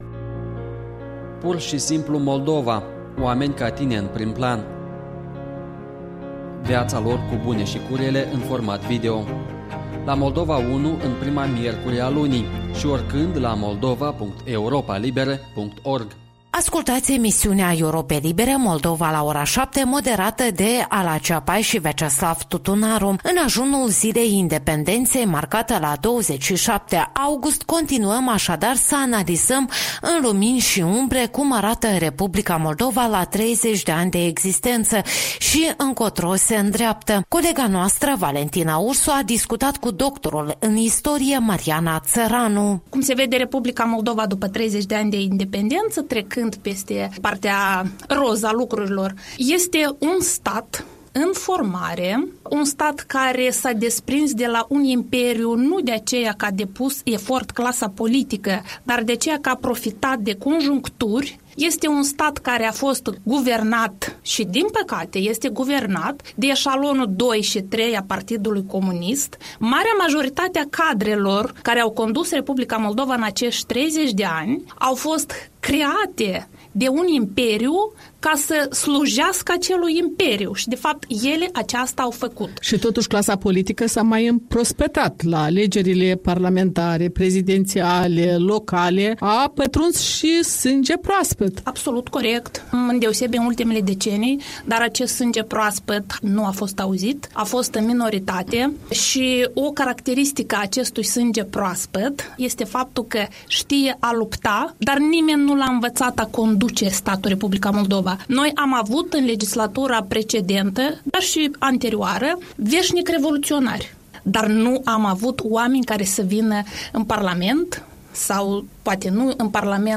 Interviul matinal